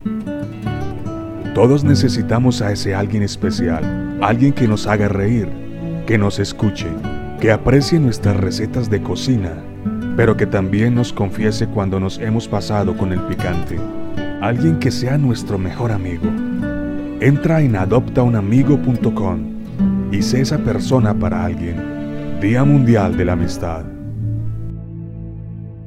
spanisch Südamerika
aa amistad tono bajo.mp3